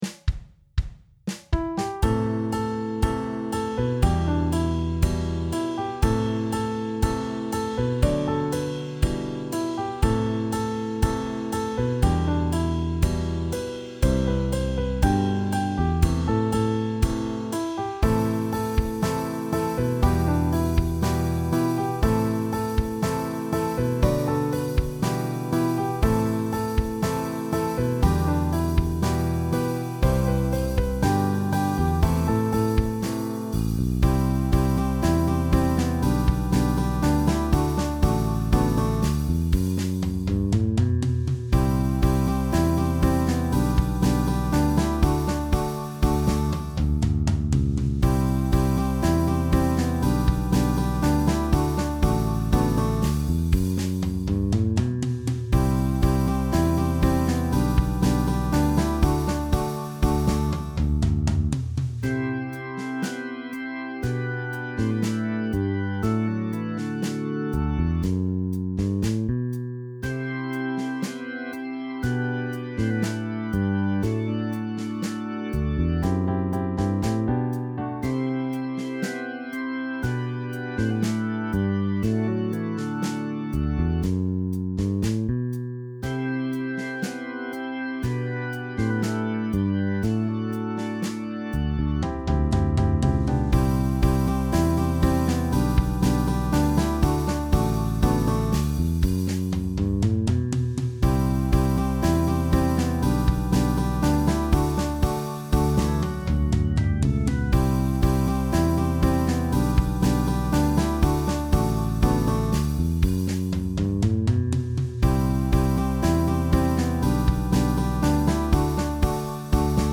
AcousticBallad
이 노래도 이전 노래와 마찬가지로 치유의 노래입니다.